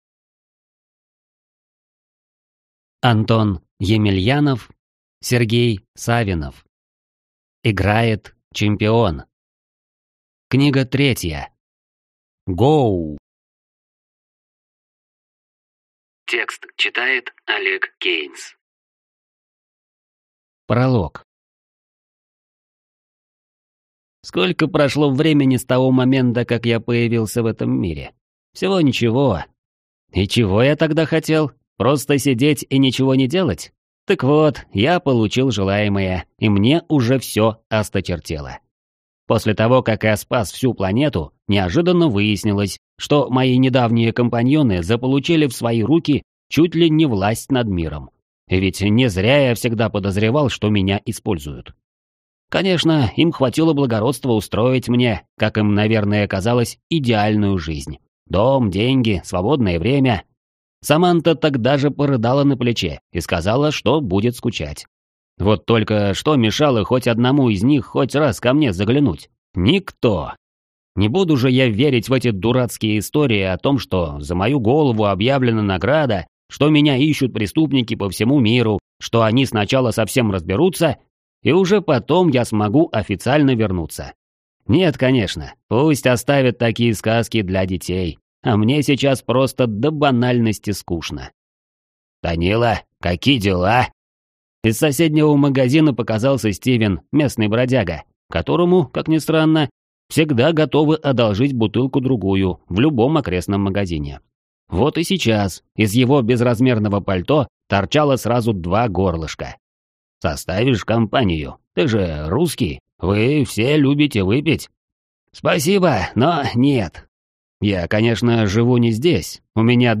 Аудиокнига Играет чемпион 3. GO!